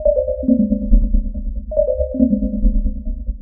tx_synth_140_gentle_CMin.wav